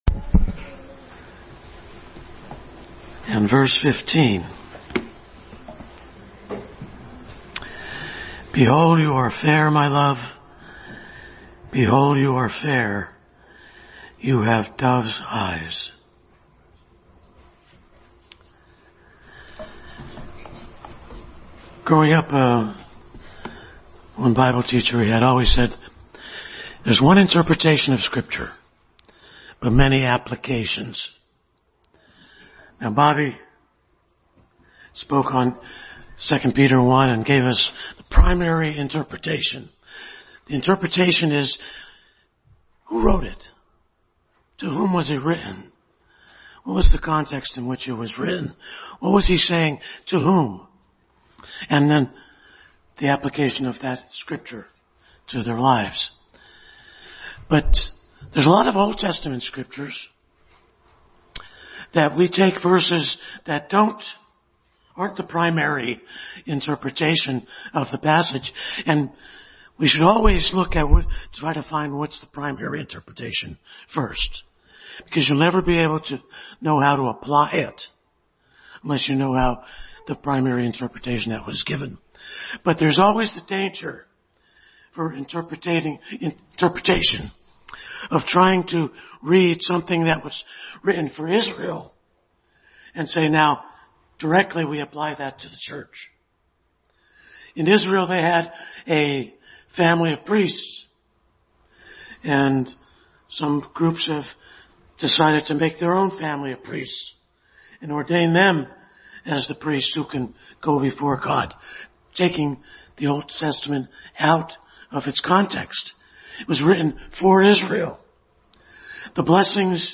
Dove’s Eyes Passage: Song of Solomon 1:15, Genesis 31:42, Galatians 1:16 Service Type: Family Bible Hour